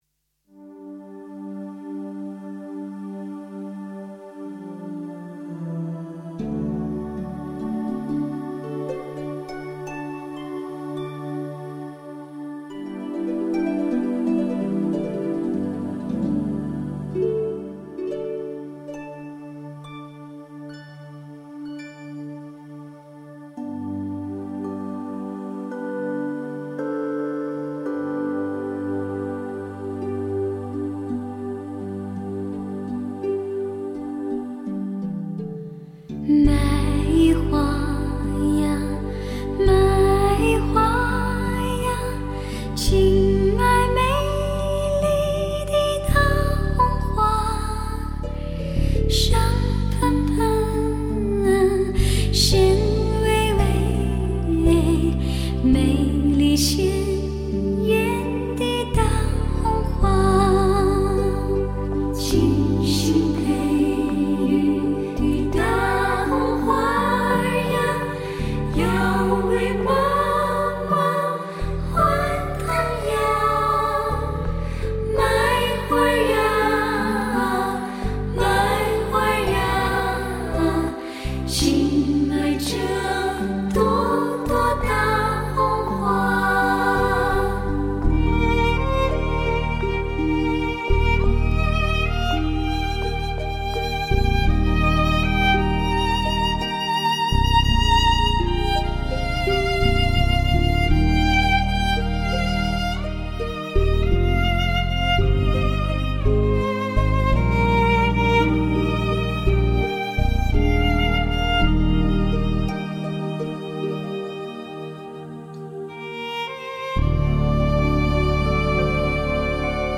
女声三重唱
笛子伴奏
吉它伴奏
手风琴伴奏
弦乐伴奏
CEP录音棚  柏菲录音棚